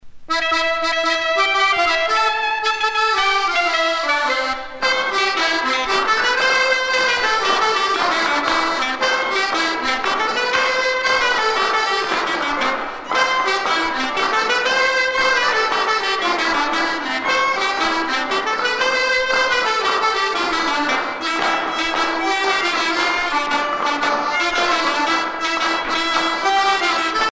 Suite de danses
Usage d'après l'analyste gestuel : danse
Catégorie Pièce musicale éditée